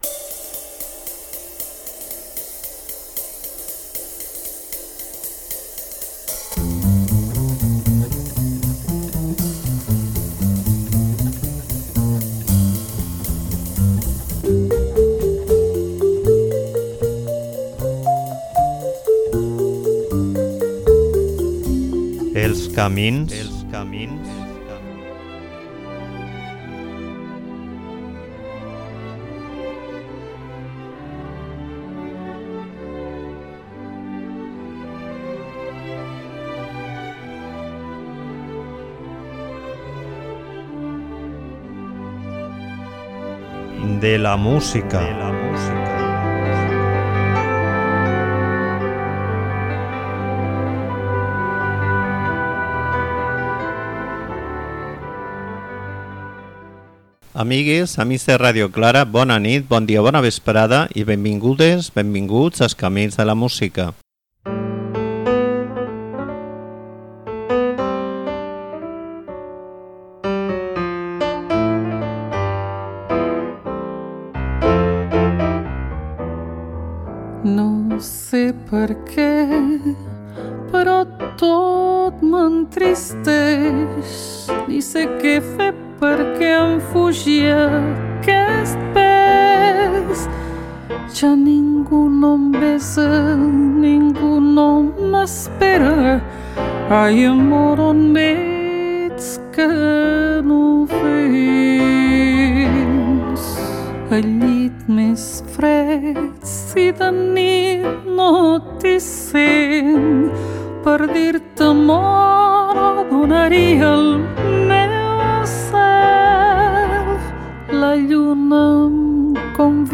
Jazz mediterrani: Manel Camp i Mª del Mar Bonet